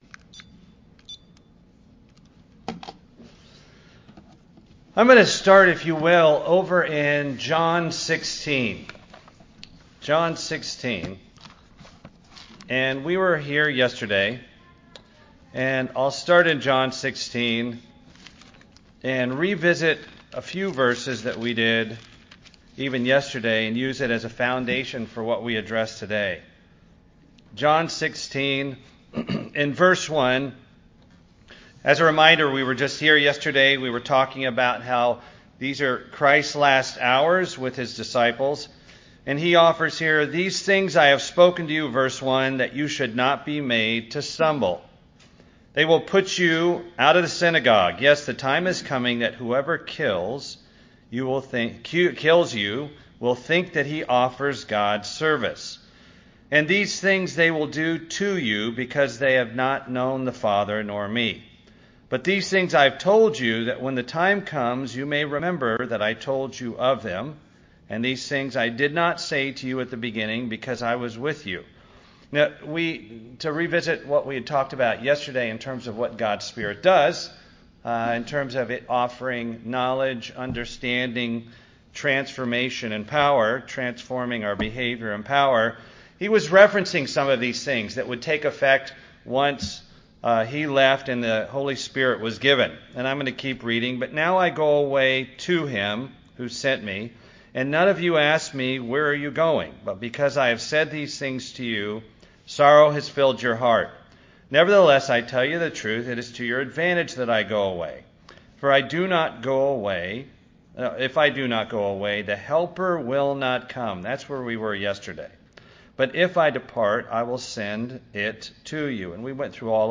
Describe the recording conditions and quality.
Given in Atlanta, GA